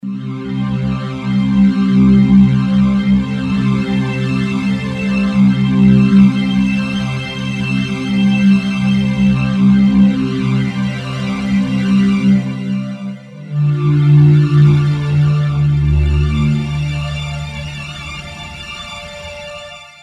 Digital rack effect full of choruses and complex modulation effects including famous Roland RSS dimensional, a proprietary technology based on psychoacoustic algorithms.
demo3 rotary pad